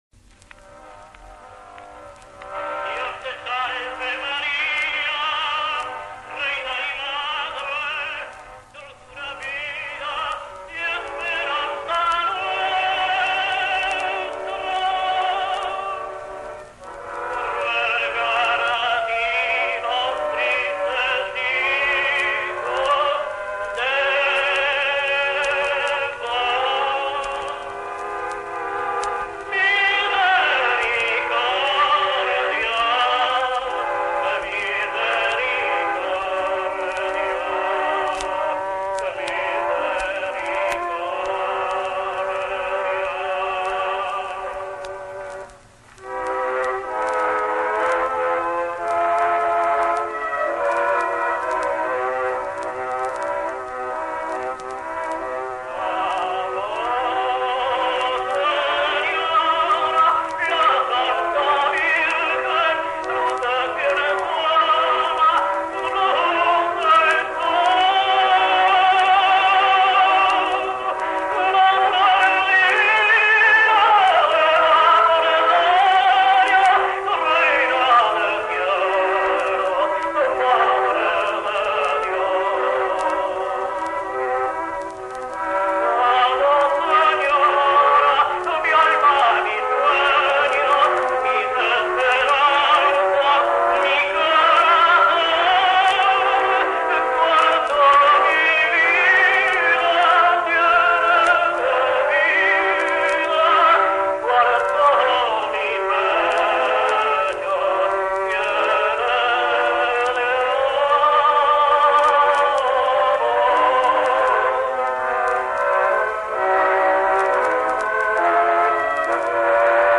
Hipólito Lázaro sings
1959 live recording, at 72 years old!!!